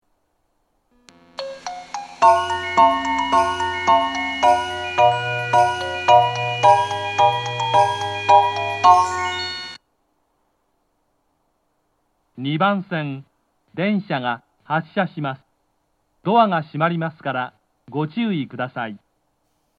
余韻切りです。